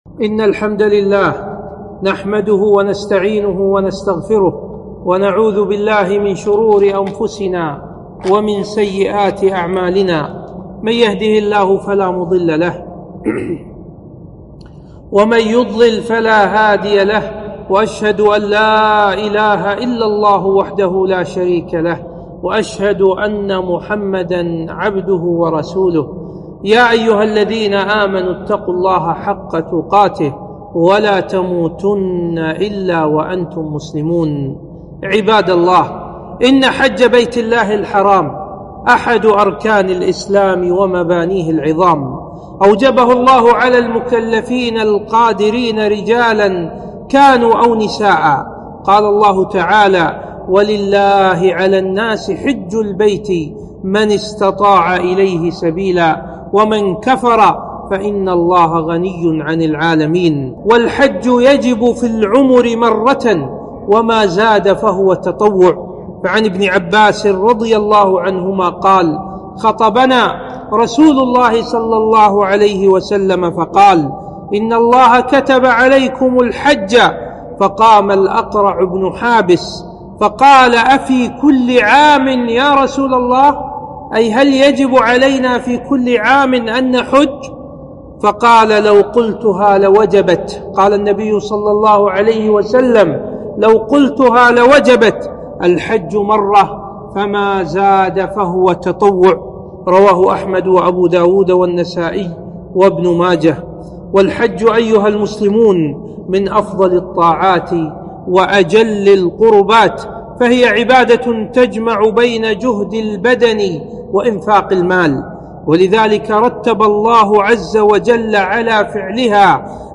خطبة - فضل الحج وحكمه وشروطه